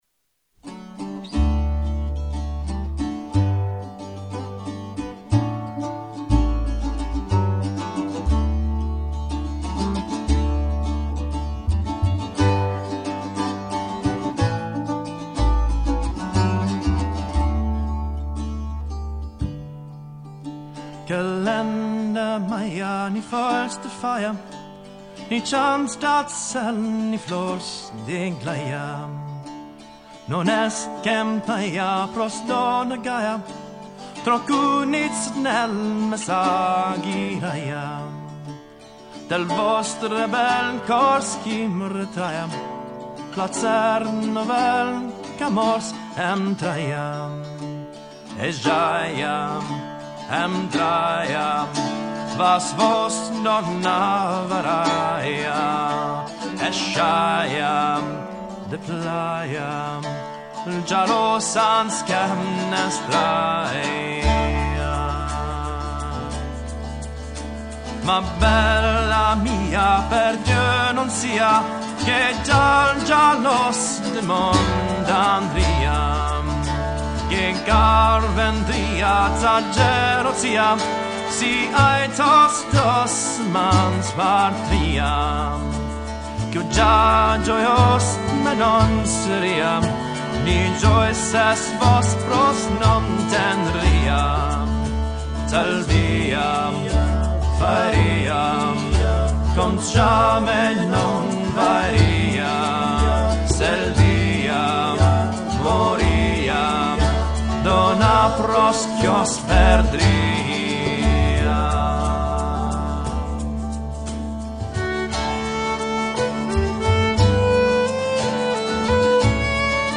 Chanson [